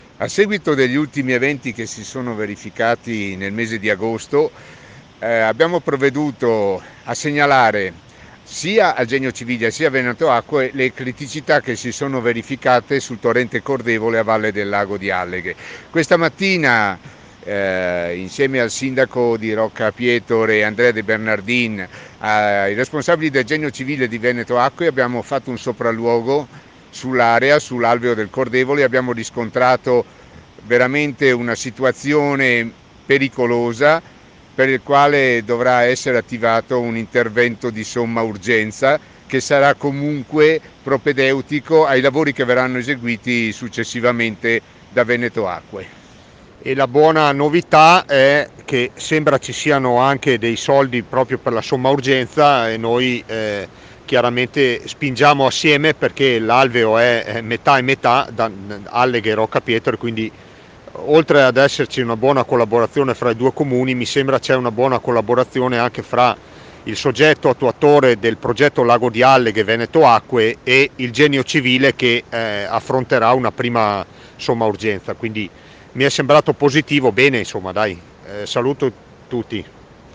DANILO DE TONI, SINDACO DI ALLEGHE, E ANDREA DE BERNARDIN, SINDACO DI ROCCA PIETORE